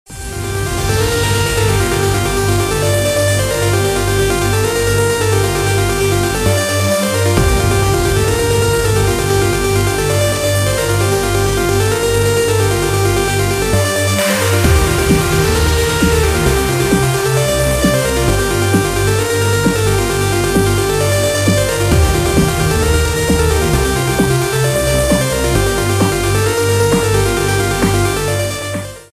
• Качество: 128, Stereo
громкие
Electronic
EDM
электронная музыка
без слов
Trance
electro house